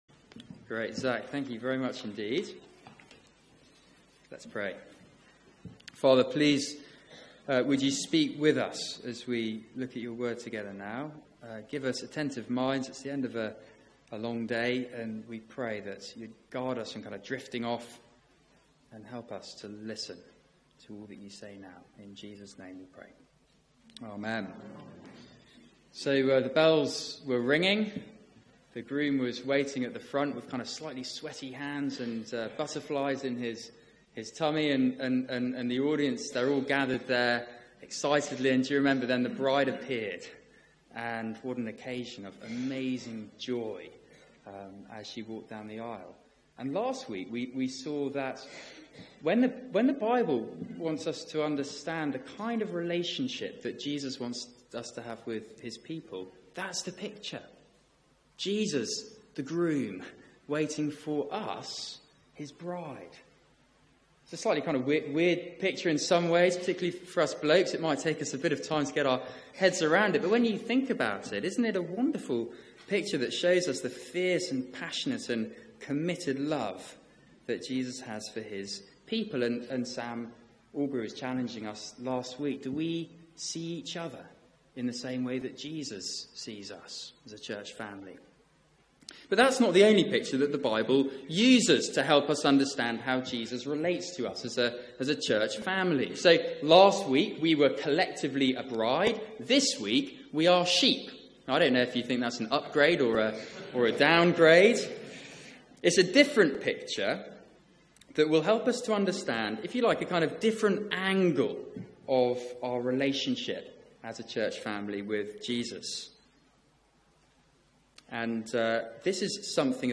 Media for 6:30pm Service on Sun 15th Jan 2017 18:30 Speaker
Series: Christ and his church Theme: Christ leads his flock Sermon